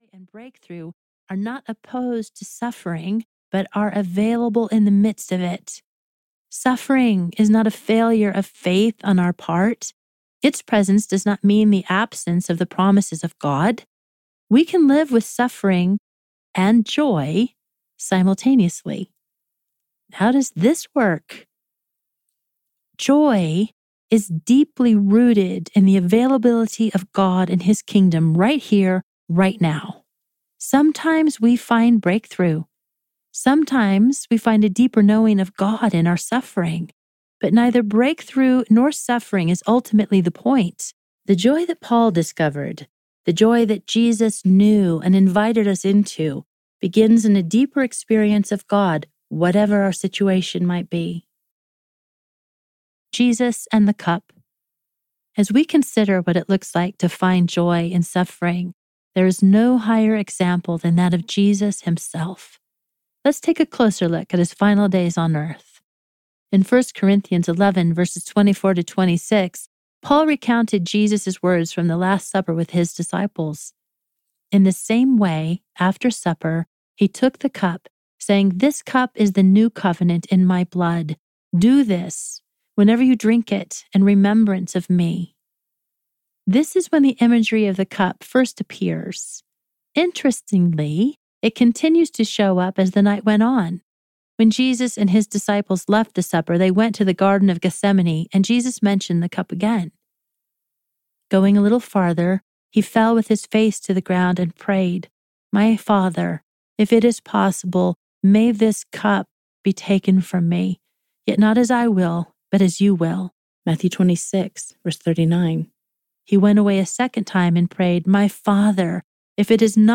Defiant Joy Audiobook